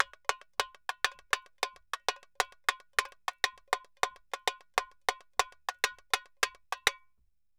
Tamborin 1_Samba 100_2.wav